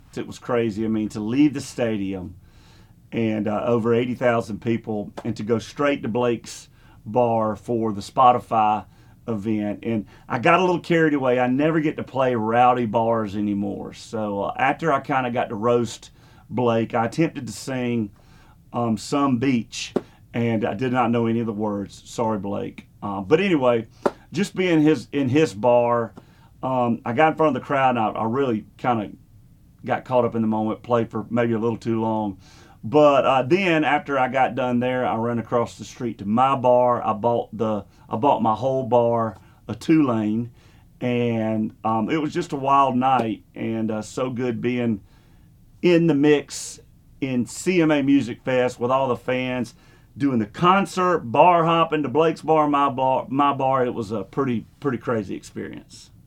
Audio / Luke Bryan talks about the "rowdy" experience he had during this year's CMA Music Fest.